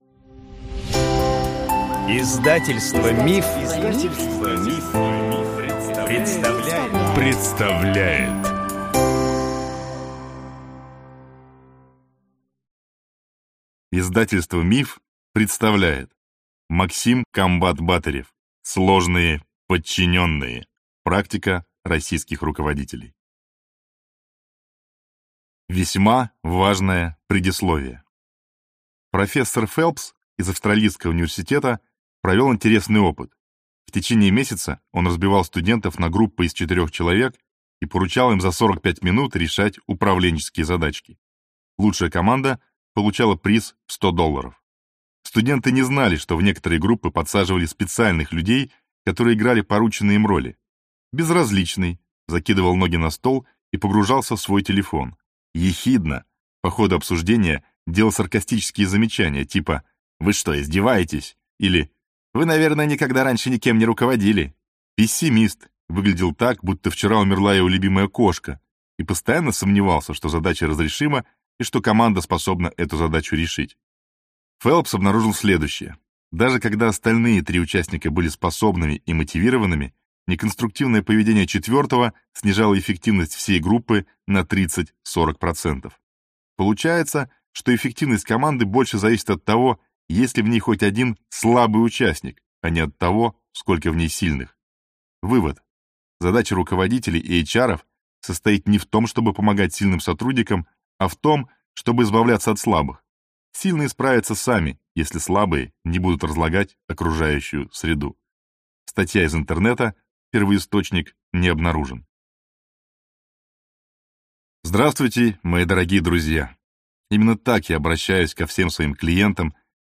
Аудиокнига Сложные подчиненные. Практика российских руководителей | Библиотека аудиокниг